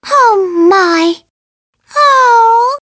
One of Princess Peach's voice clips in Mario Kart 7